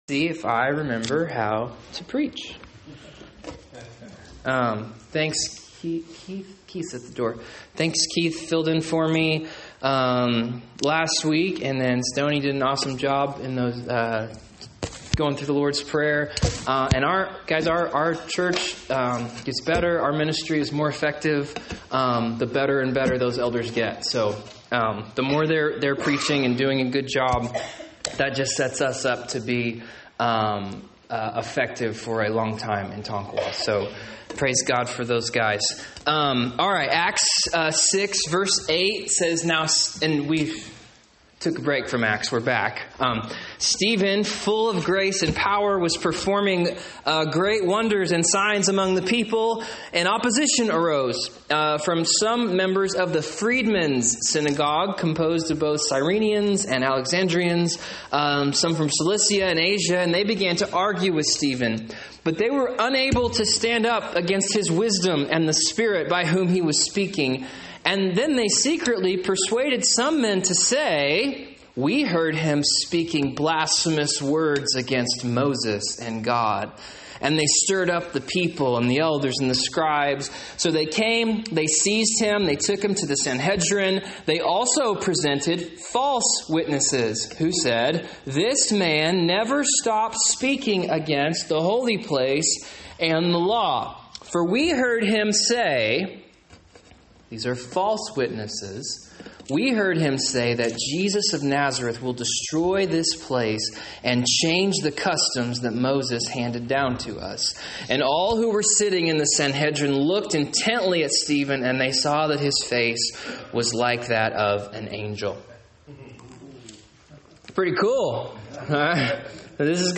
Sermons | Christian Life Church